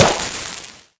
player_dodge_success_alt.wav